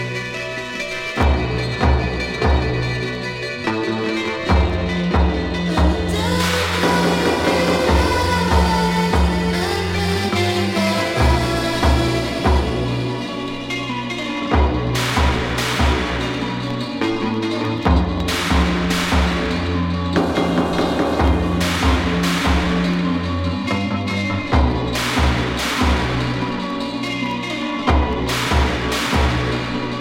de tradição gótica apurada
as caixas de ritmos não perdoam na cadência marcial